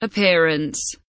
appearance kelimesinin anlamı, resimli anlatımı ve sesli okunuşu